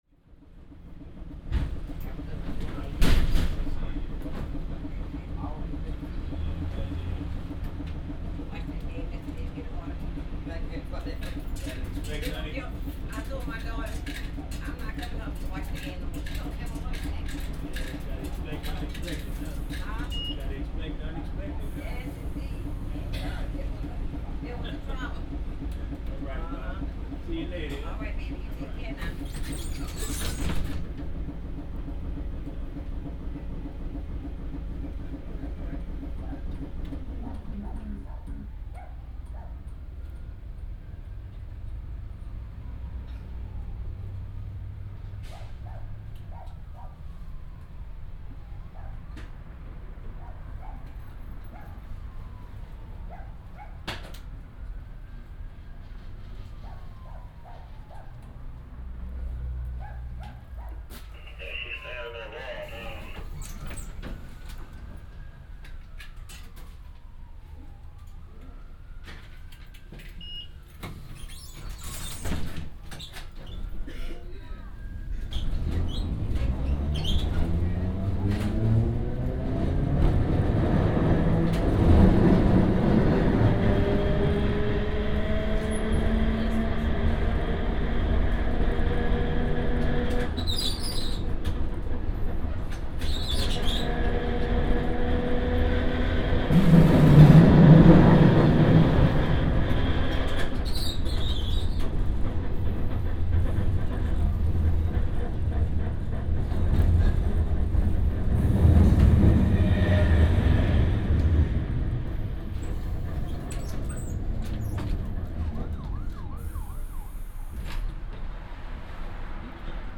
Micrófono binaural
Neste caso se trata do son no interior dun tranvía en Nova Orleáns (EE.UU.).
Tranvia_nola.mp3